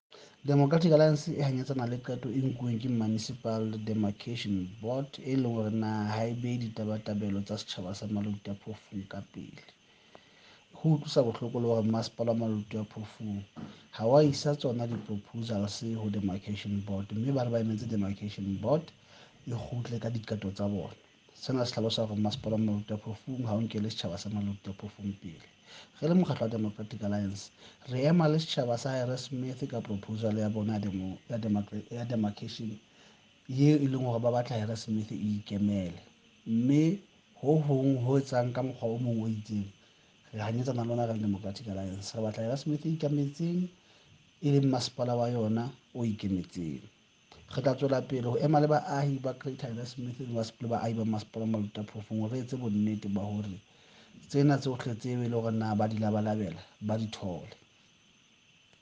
Sesotho by Cllr Moshe Lefuma and